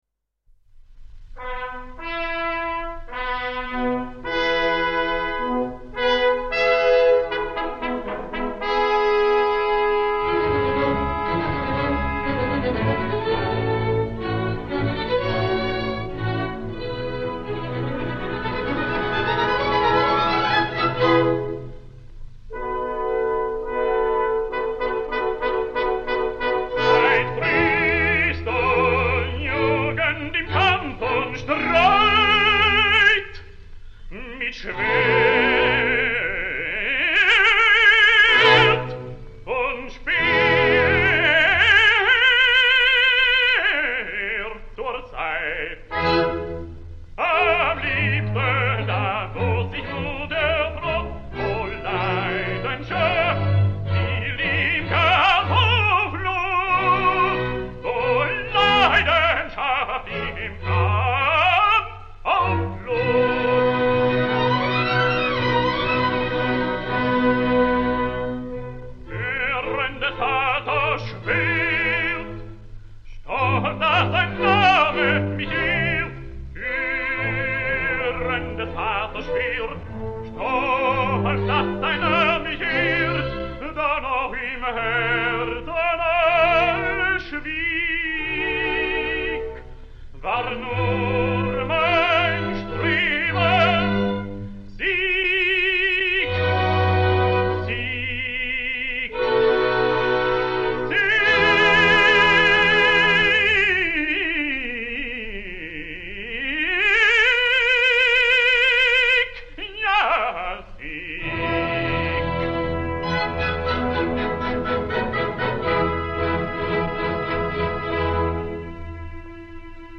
També us posaré la versió de Helge Rosvaenge que em sembla digne d’audició, procedent d’un recopilatori d’àries alemanyes gravades entre els anys 1941-1943. Dirigeix l’orquestra Artur Rother.